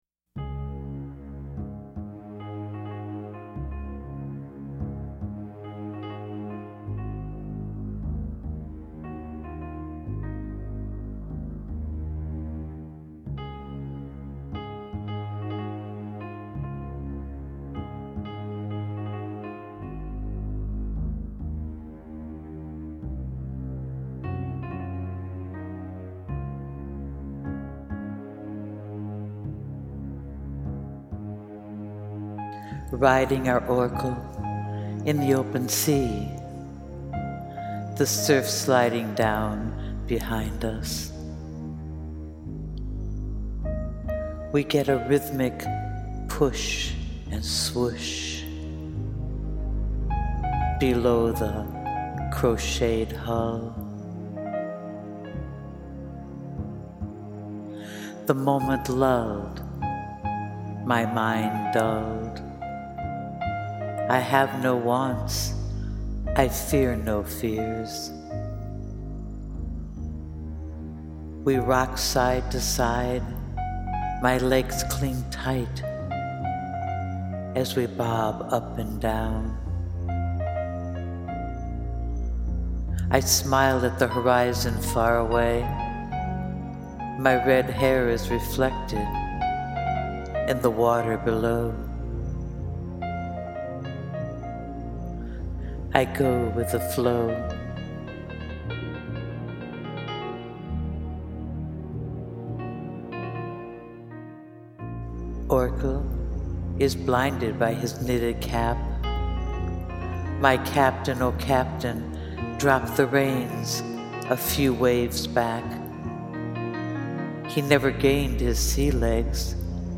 Please listen to the poem recorded to the haunting music of Leonard Cohen’s,”Tacoma Trailer”
your reading of the poem (oh the perfect choice of background music!🥰) is just lovely & gently, peacefully, takes me on the journey with you🙏🏼 ah, yes, those last 4 lines ❤: